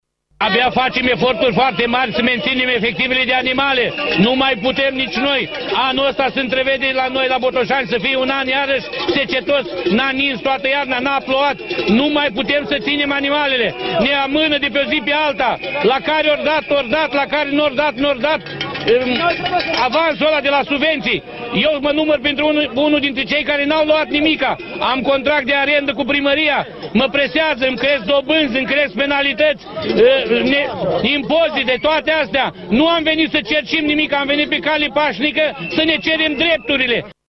Câteva sute de fermieri protestează la această oră în parcul Izvor din Capitală, în fața Palatului Parlamentului.
Unul dintre fermierii prezenți la protest, despre nemulțumirile acestora: